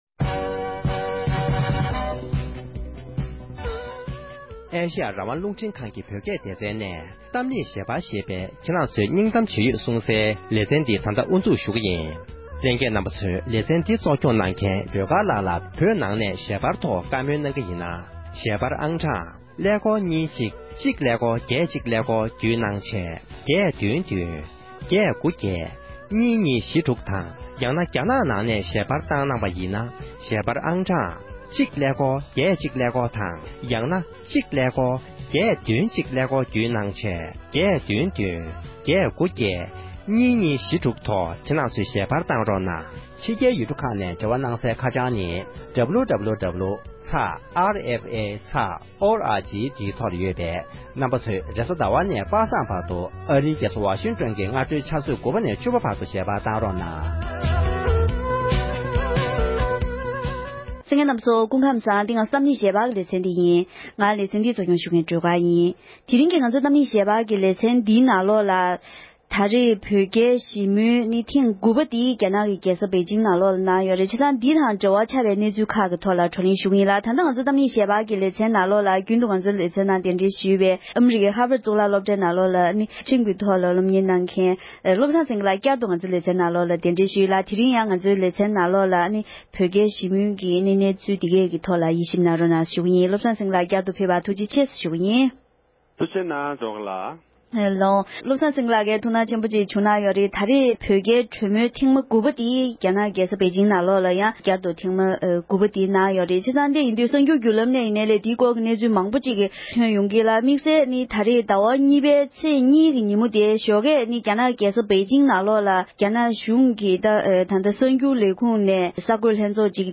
བོད་རྒྱ་འབྲེལ་མོལ་ཐེངས་དགུ་པའི་ཐོག་གྲུབ་འབྲས་ཇི་བྱུང་ཡོད་མེད་སྐོར་བགྲོ་གླེང༌ཞུས་པ།